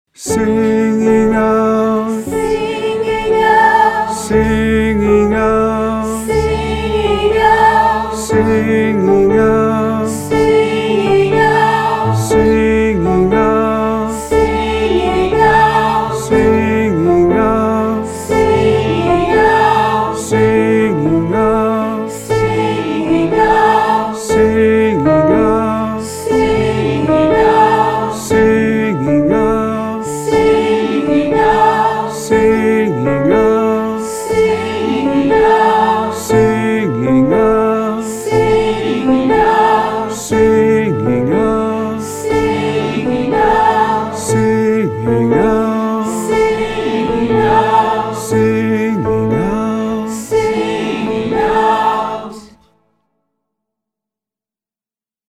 Chromatic Singing out w group